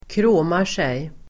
Ladda ner uttalet
kråma sig verb, strut, preenGrammatikkommentar: A &Uttal: [kr'å:mar_sej] Böjningar: kråmade sig, kråmat sig, kråma sig, kråmar sigDefinition: stolt vrida på kroppen för att visa upp sig